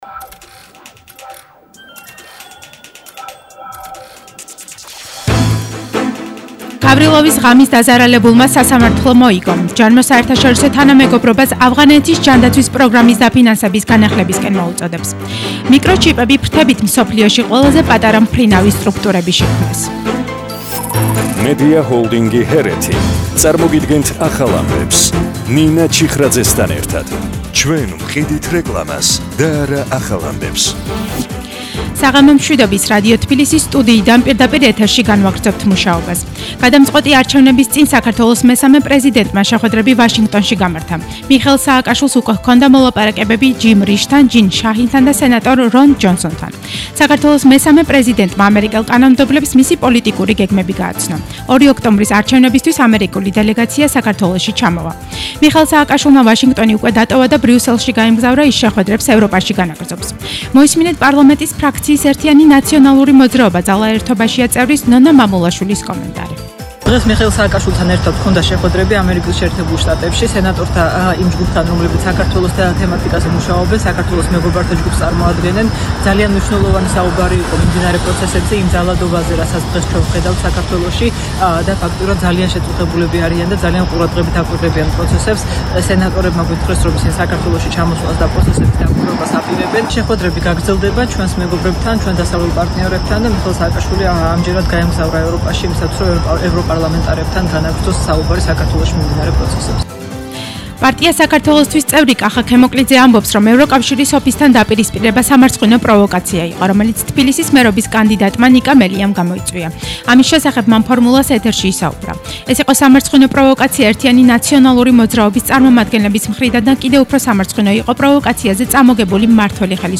ახალი ამბები 20:00 საათზე –23/09/21